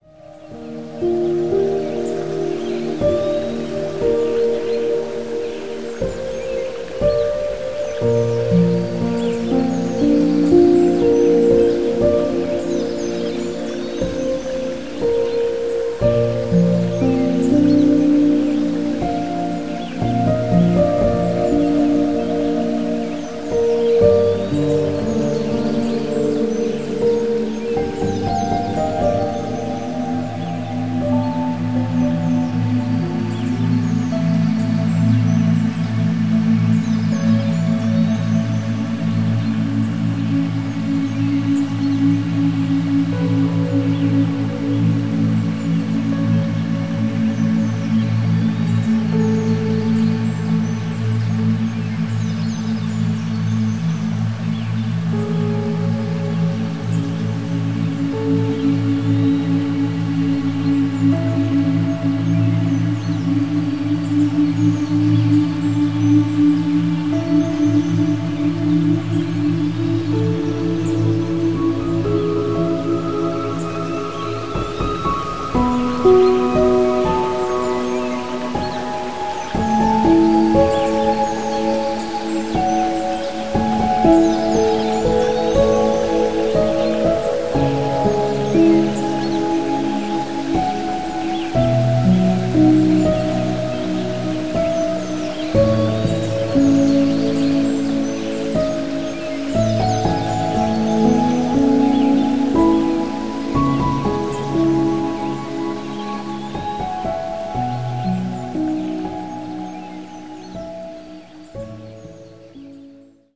Die Geräusche wurden in 44,1 kHz/Stereo aufgenommen.
44.1 kHz / Stereo Sound